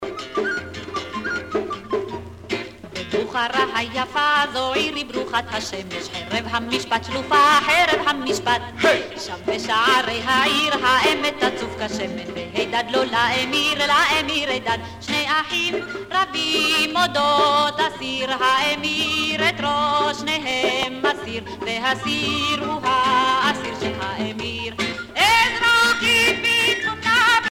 Influence du folklore sépharade (judéo-espagnol et oriental)
Pièce musicale éditée